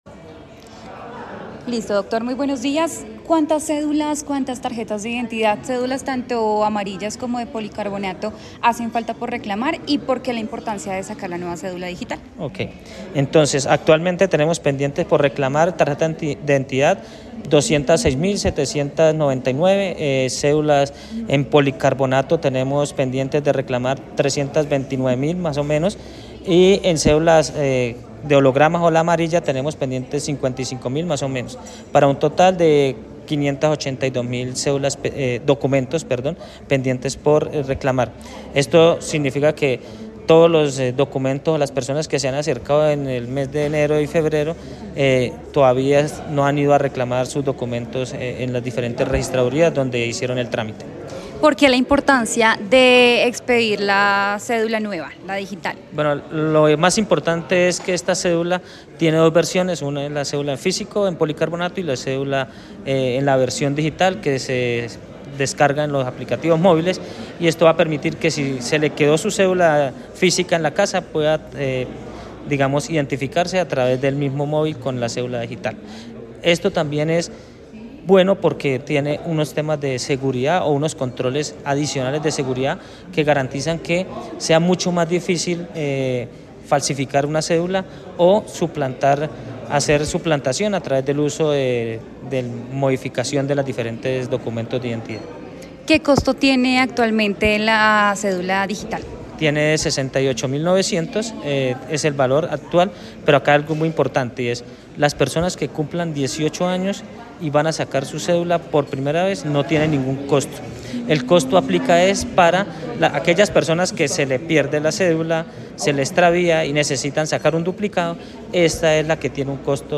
Hoslander Sáenz, registrador delegado para el Registro Civil y la Identificación, se refirió en W Radio al represamiento de documentos en la entidad.